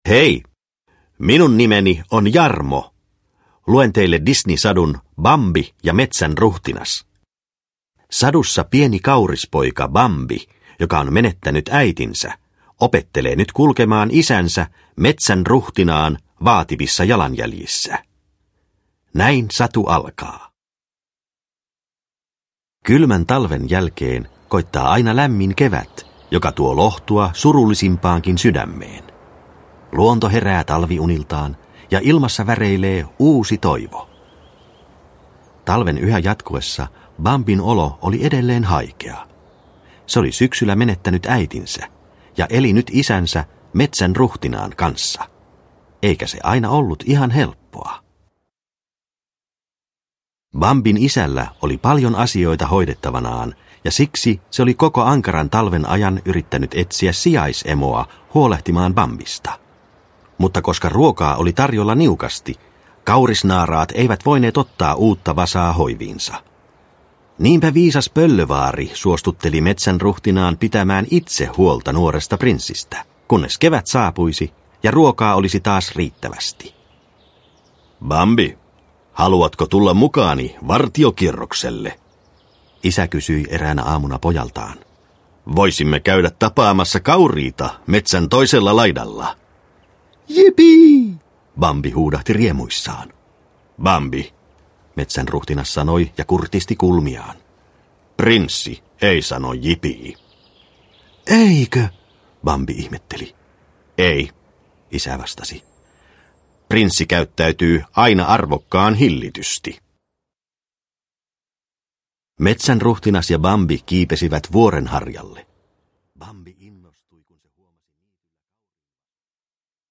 Bambi ja metsän ruhtinas – Ljudbok – Laddas ner